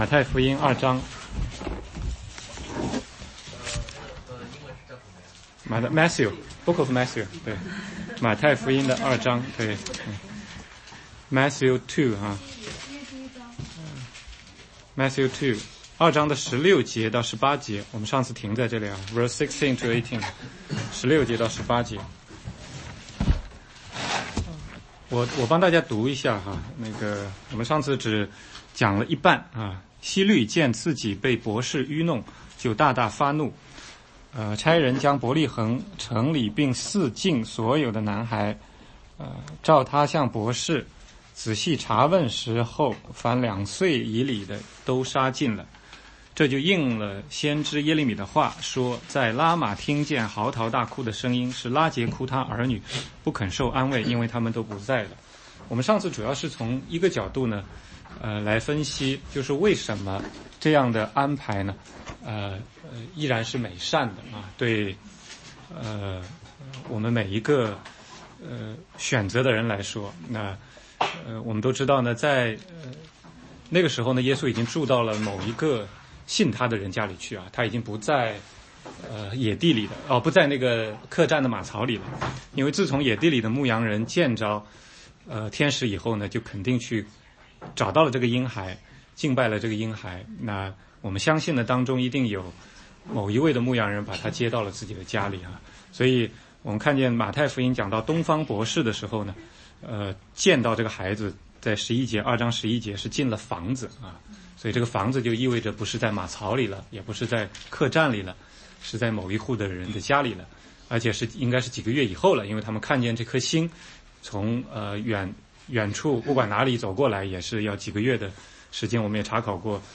16街讲道录音 - 马太福音2章16-23